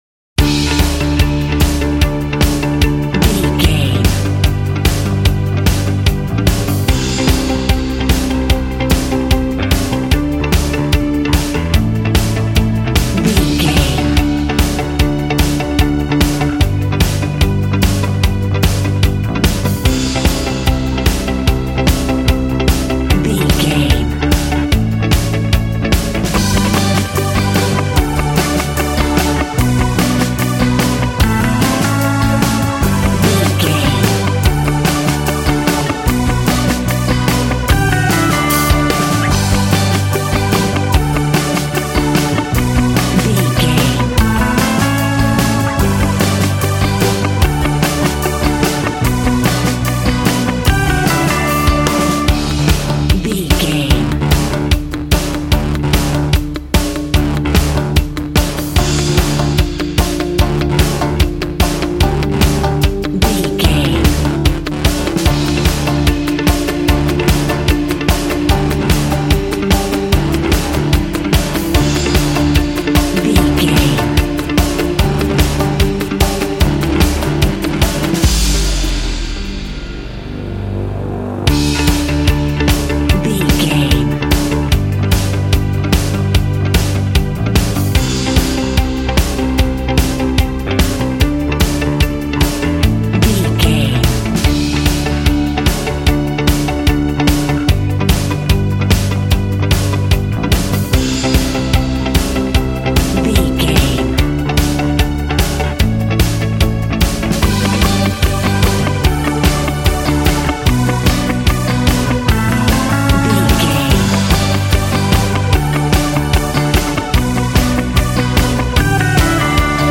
Ionian/Major
D
groovy
powerful
organ
bass guitar
electric guitar
piano